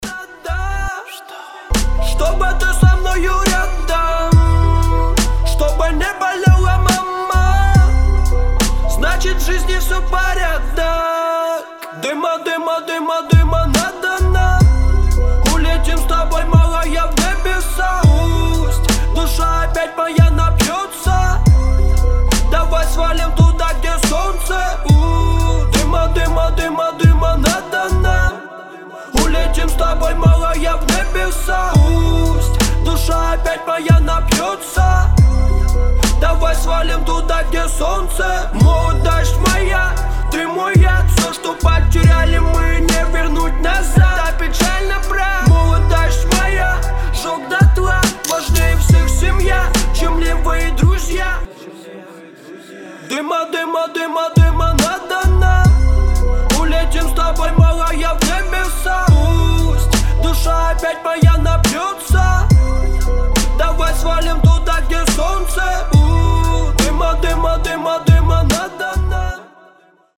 • Качество: 320, Stereo
русский рэп
пацанские